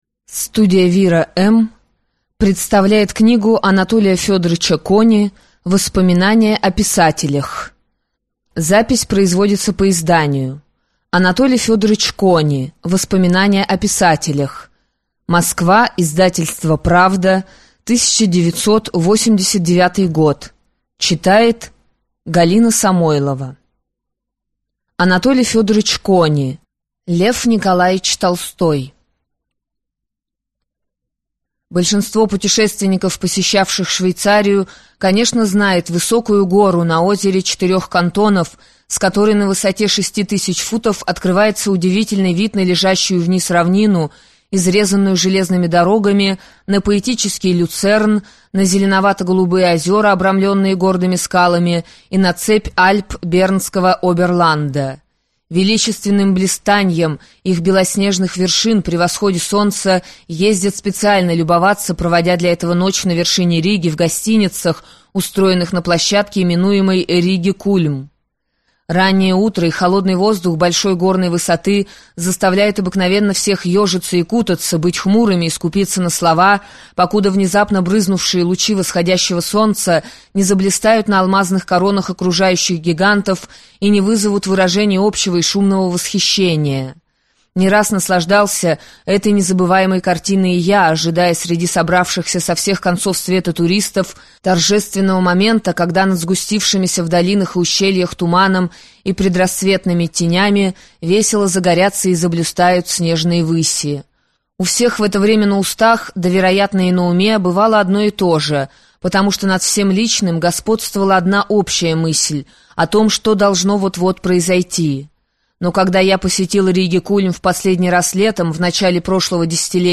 Аудиокнига Лев Николаевич Толстой | Библиотека аудиокниг